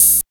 80 OP HAT 2.wav